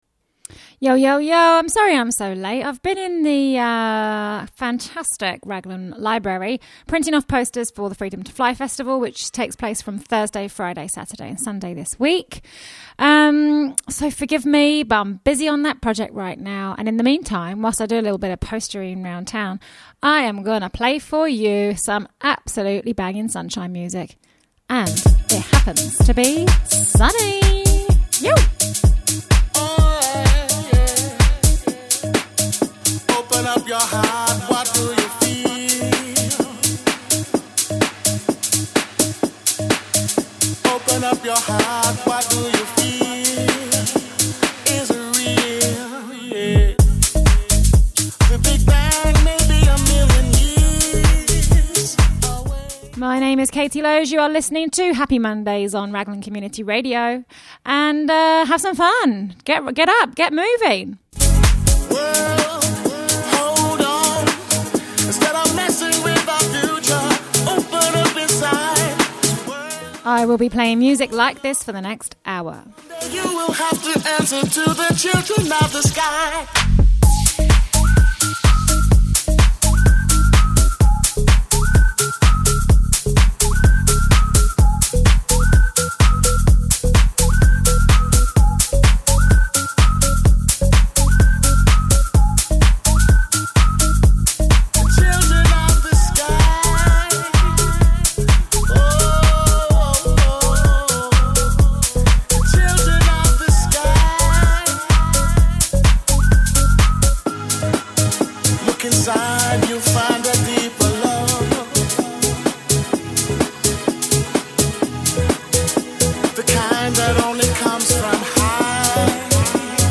Chats about Freedom 2 Fly Fest 2024 plus sunshine big tuuuunes